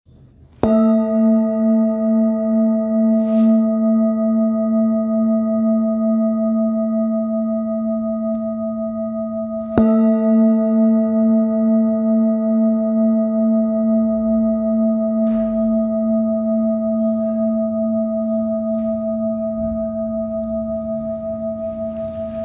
Singing Bowl, Buddhist Hand Beaten, with Fine Etching Carving, Samadhi, Select Accessories
Material Seven Bronze Metal